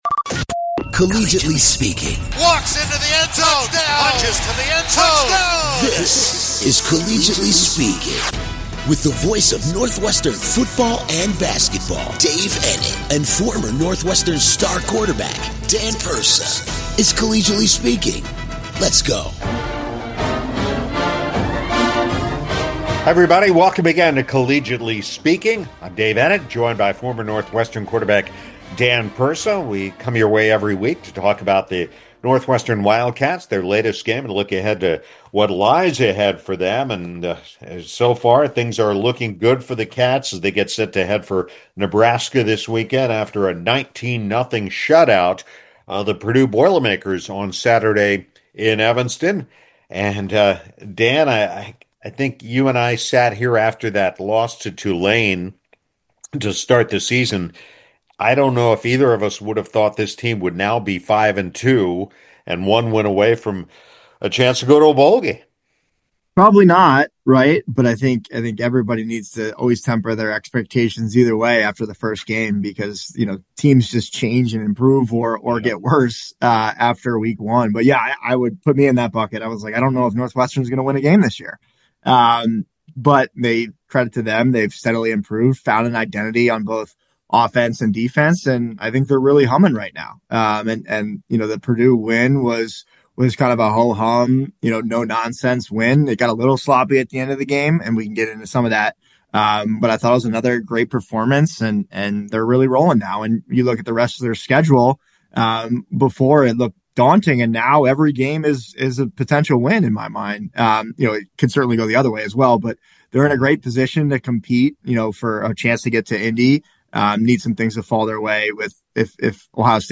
Northwestern Wildcats vs. Central Michigan Chippewas – GameAbove Sports Bowl – Ford Field – Detroit, Michigan – December 26, 2025